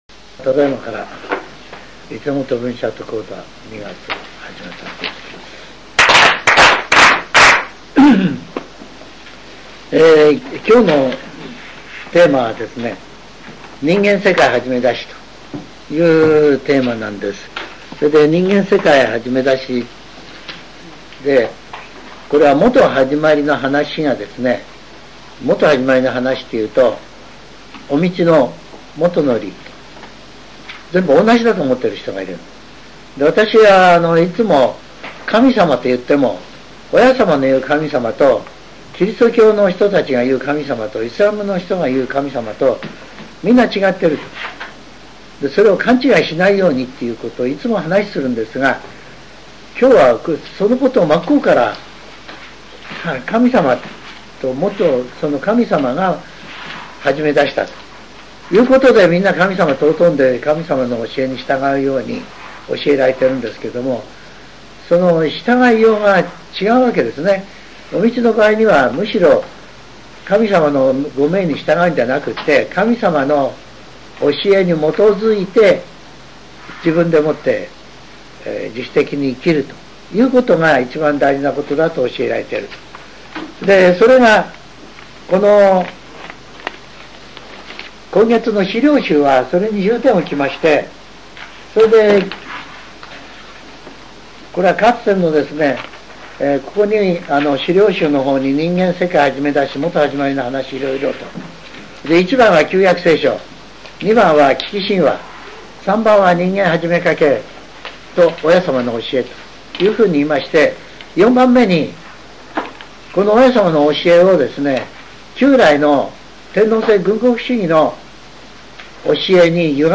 全70曲中57曲目 ジャンル: Speech